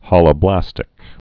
(hŏlə-blăstĭk, hōlə-)